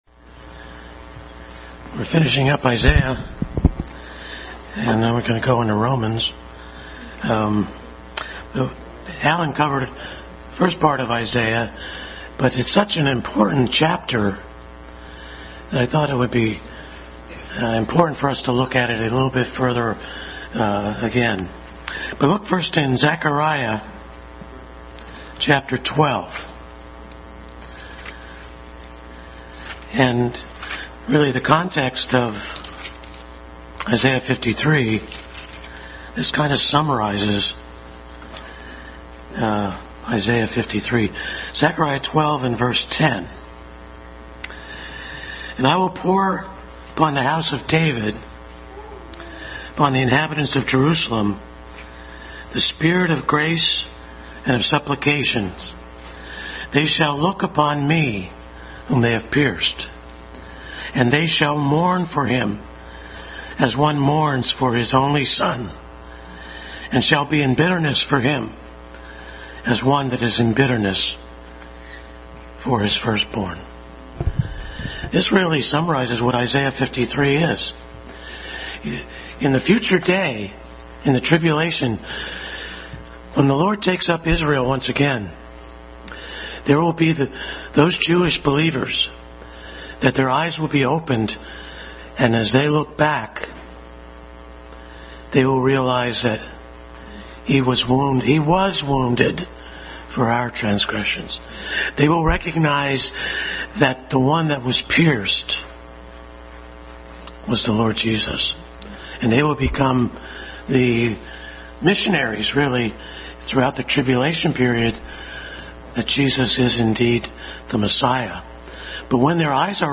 Adult Sunday School Class continued study of Christ in Isaiah.